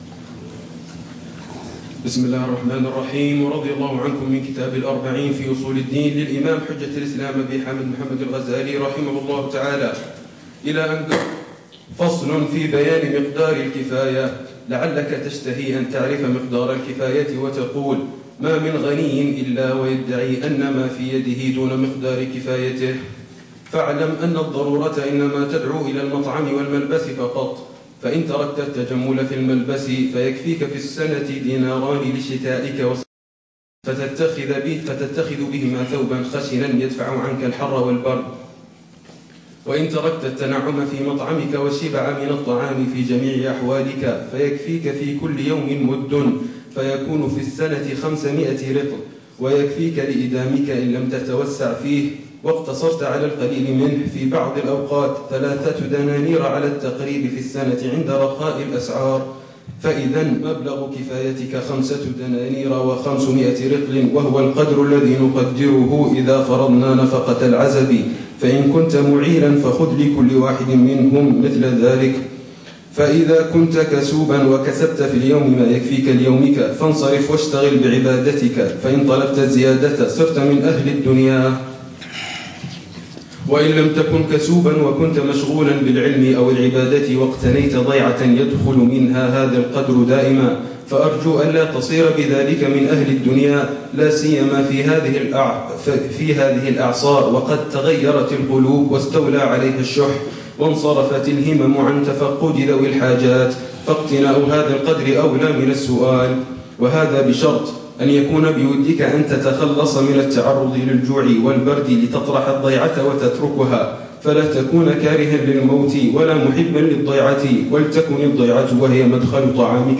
الدرس ال25 في شرح الأربعين في أصول الدين | البخل وحب المال (2): حد الكفاية، تعريف البُخل وعلاجه، والتنبيه إلى فتنة الجاه والشهرة